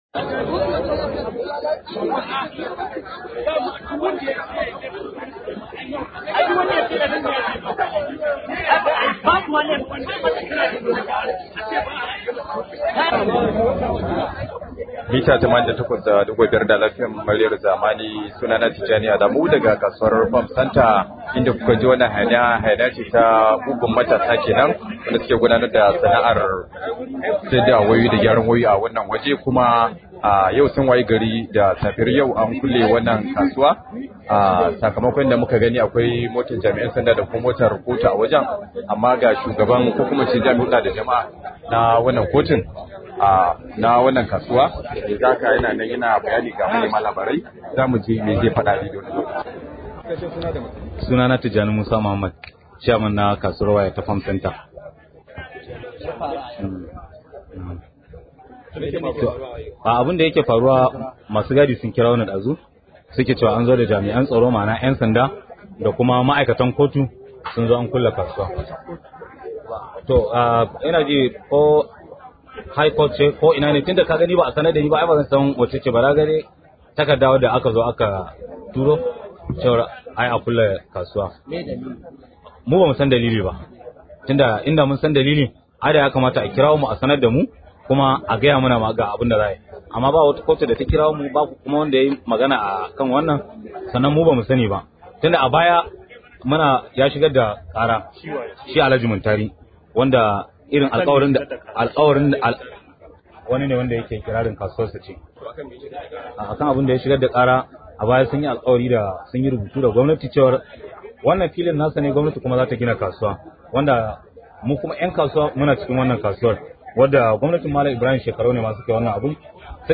Rahoto: An rufe kasuwar waya ta Farm Center